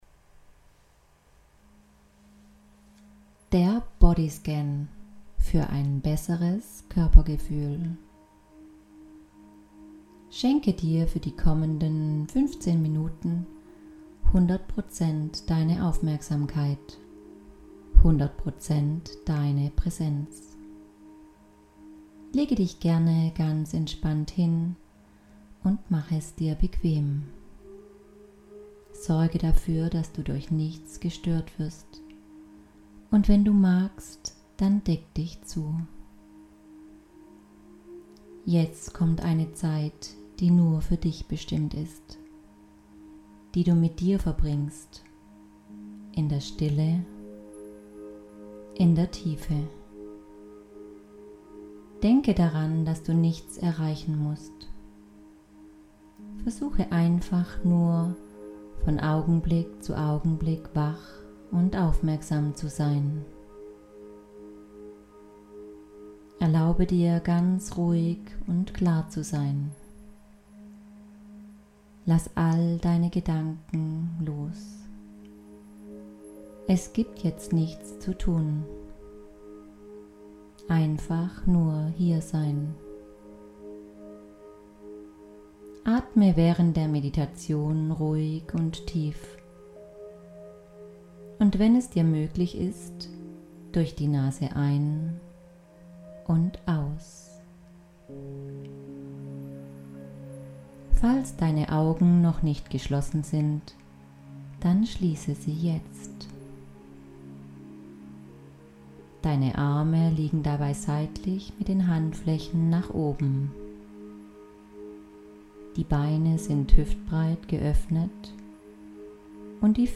Ganz neu gibt es von und mit mir geführte Meditationen.